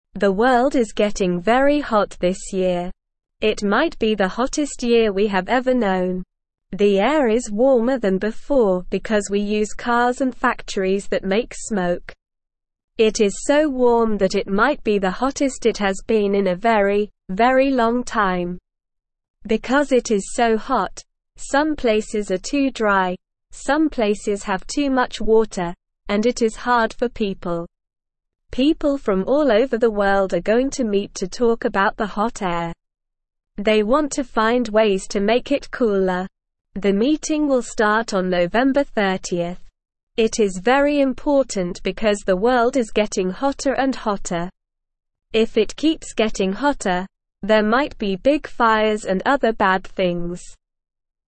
English-Newsroom-Beginner-SLOW-Reading-The-World-Is-Getting-Very-Hot-This-Year.mp3